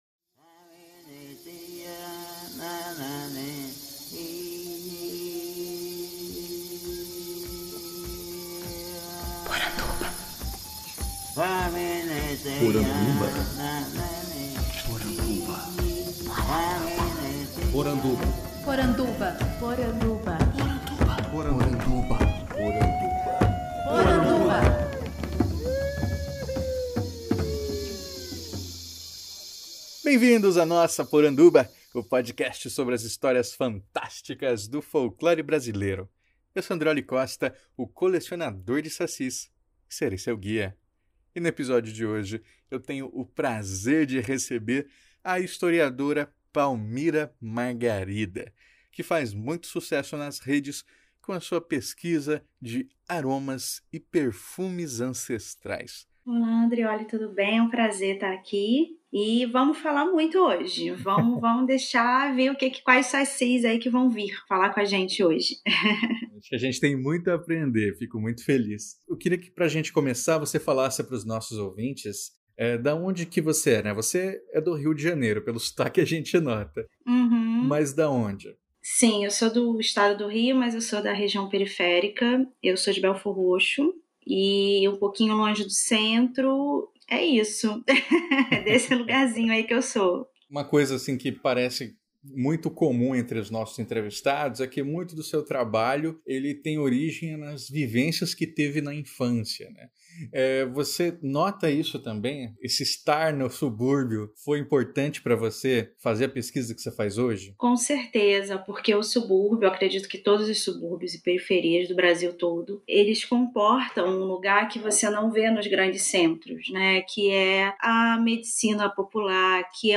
– Canto de abertura e encerramento do povo Ashaninka Poranduba agora faz parte da rede Audiocosmo de Podcasts, do grupo Homo Literatus!